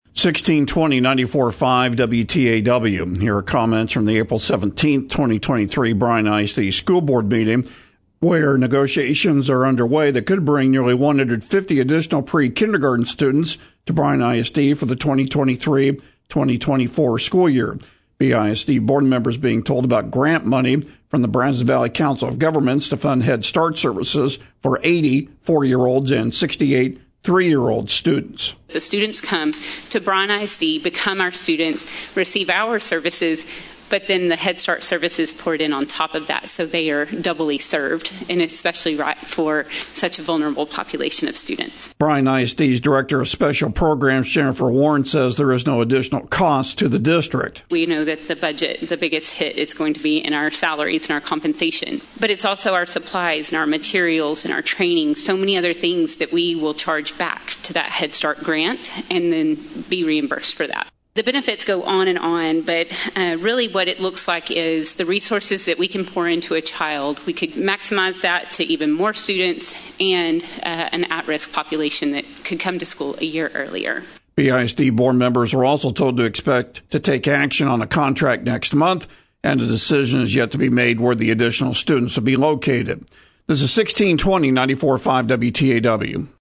BISD school board members were told Monday night about grant money from the Brazos Valley Council of Governments (BVCOG) to fund Head Start services for 80 four year olds and 68 three year old students.